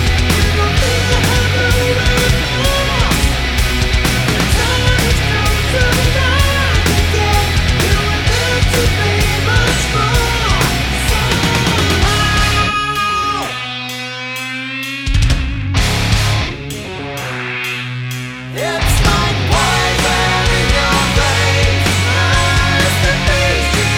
no Backing Vocals at 2m40s Rock 4:17 Buy £1.50